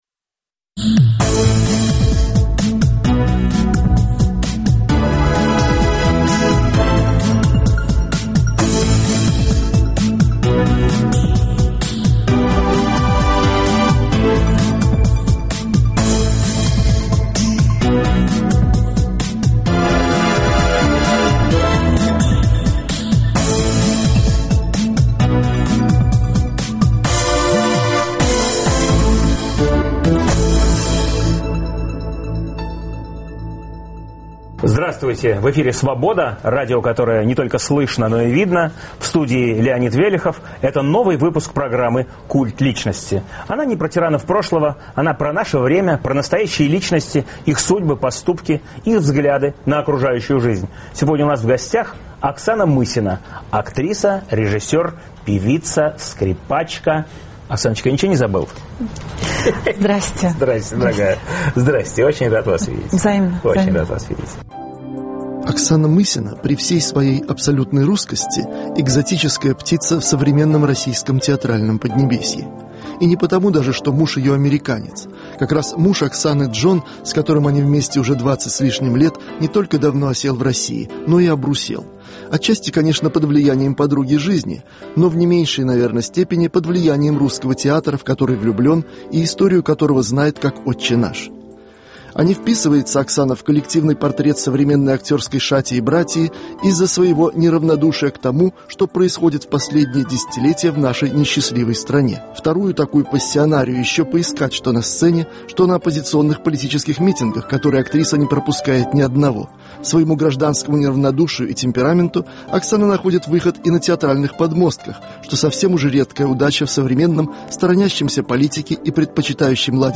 Культ личности - это программа о настоящих личностях, их судьбах и взглядах на жизнь. В новом выпуске беседа с актрисой Оксаной Мысиной. Эфир в субботу 28 февраля в 18 часов Ведущий - Леонид Велехов.